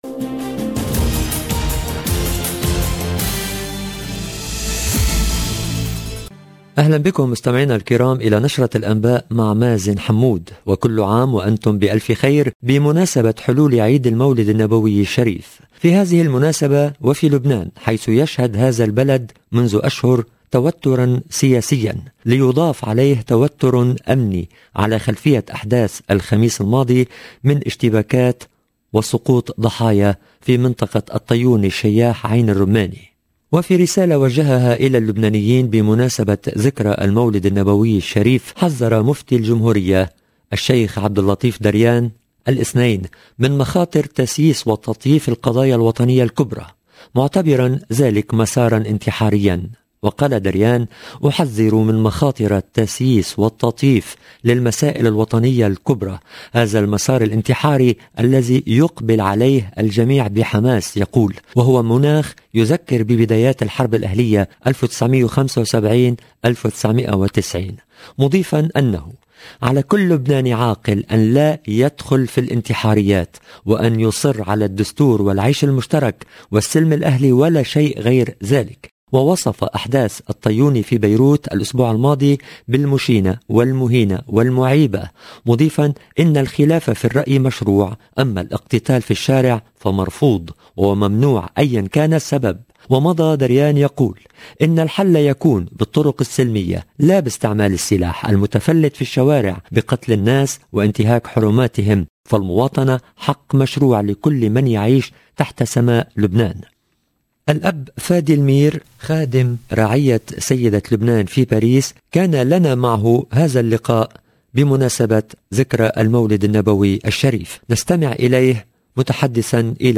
Le journal du soir en langue arabe du 18/10/21